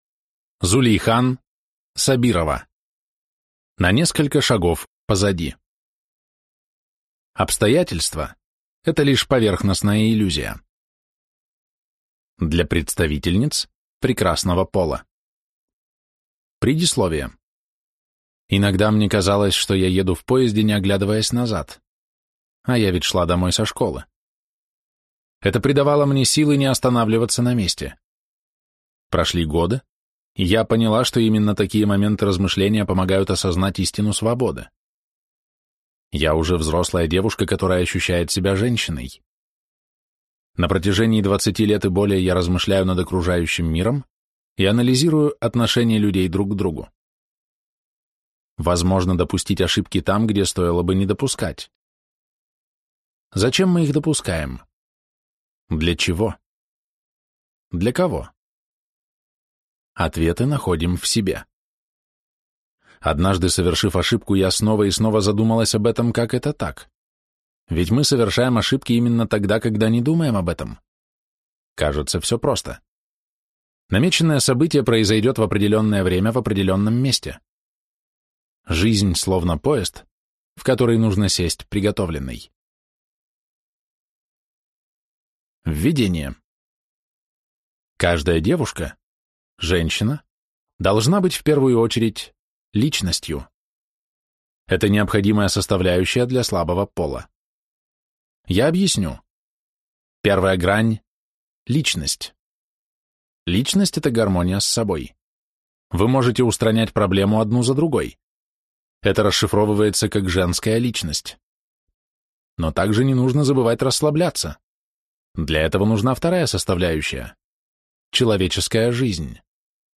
Аудиокнига На несколько шагов позади. Обстоятельство – это лишь поверхностная иллюзия | Библиотека аудиокниг